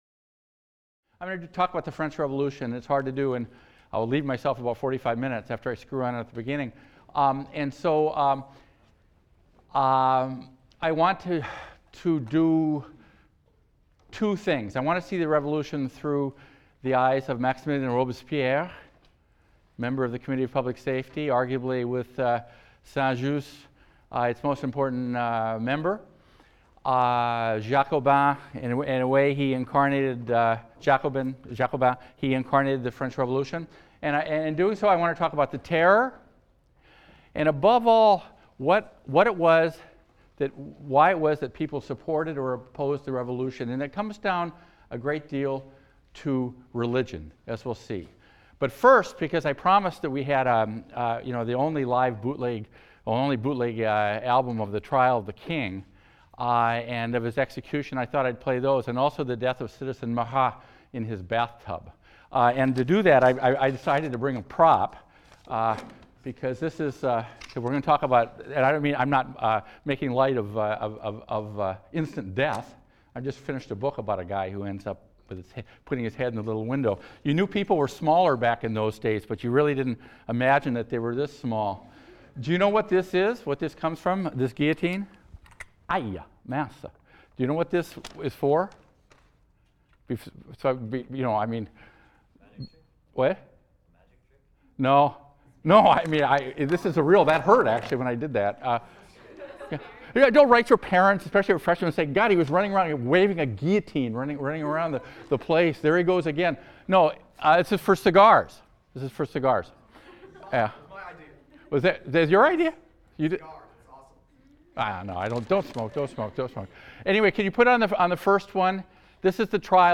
HIST 202 - Lecture 6 - Maximilien Robespierre and the French Revolution | Open Yale Courses